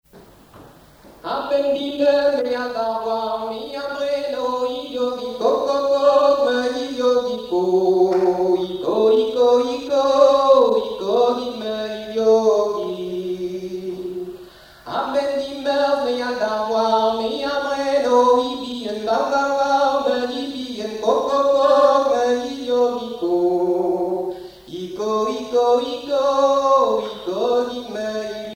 Genre laisse
Chansons en breton
Pièce musicale inédite